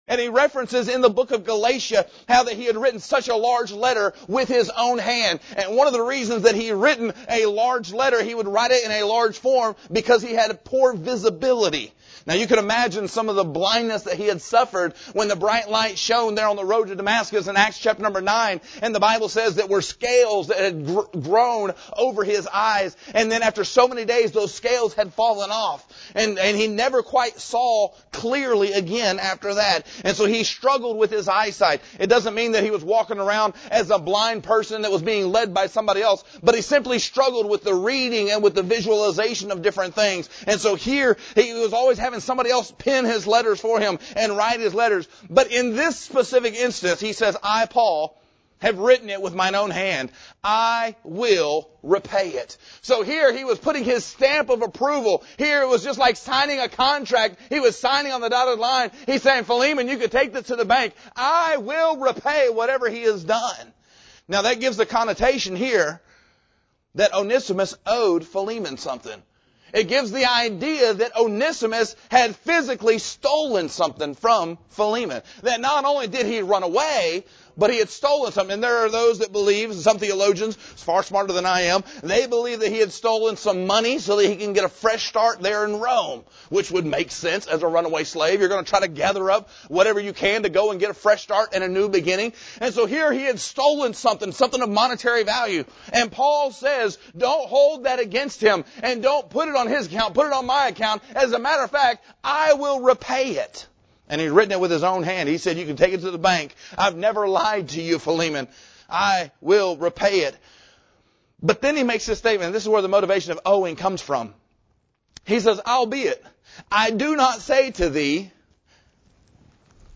This sermon is the third in a series titled “Why Should I Forgive.” We continue in the short but richly practical book of Philemon.